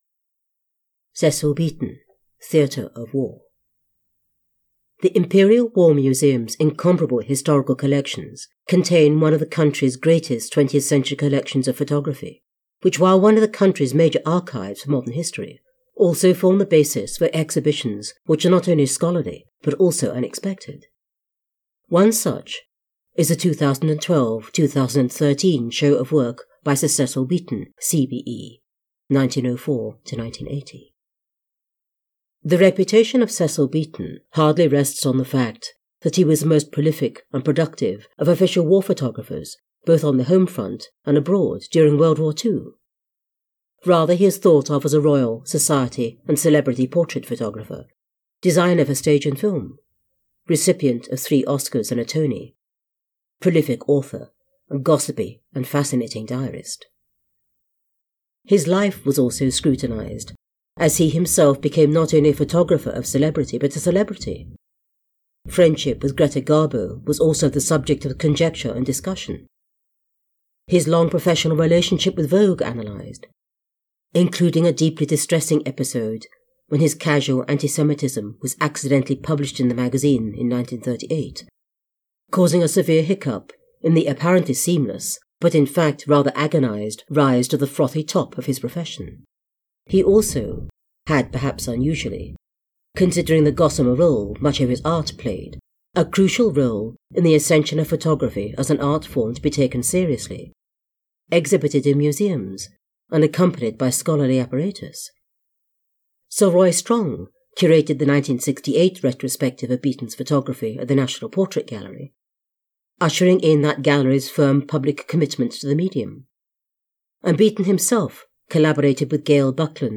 The Cecil Beaton: Theatre of War audiobook is available on Amazon, Audible and iTunes.